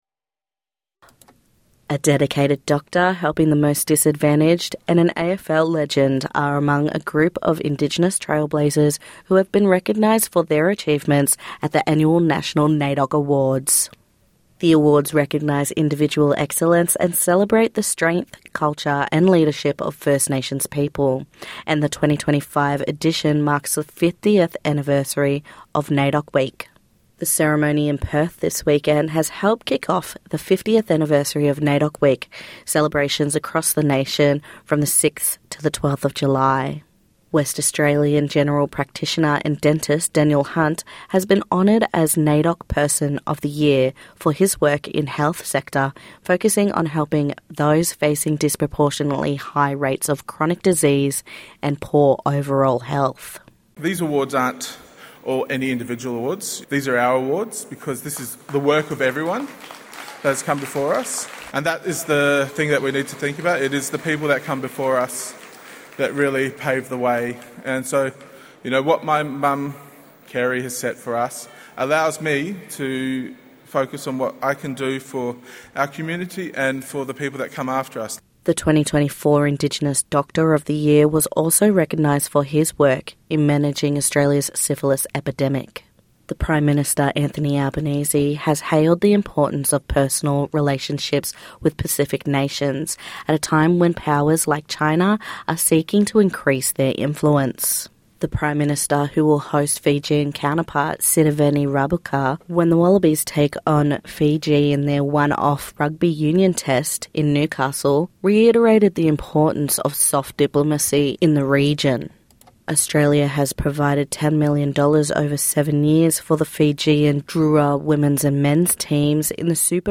NITV Radio News - 07/07/2025